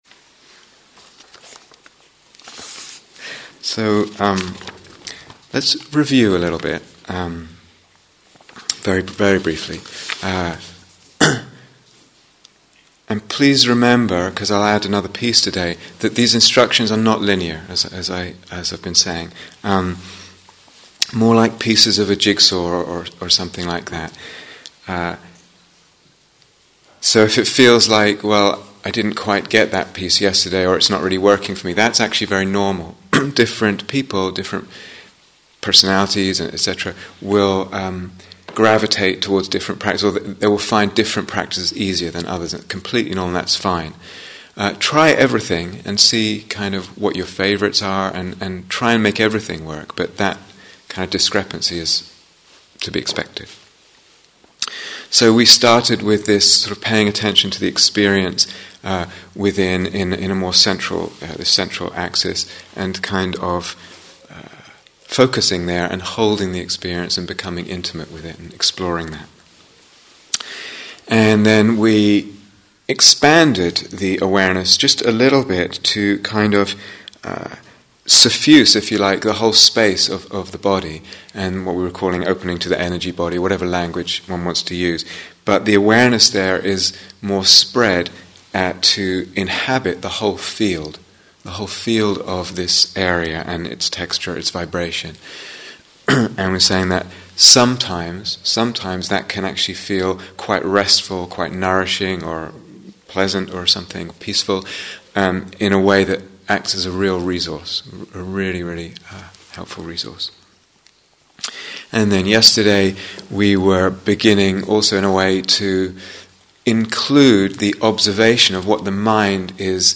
Working with the Emotional Body (Instructions and Guided Meditation: Day Four)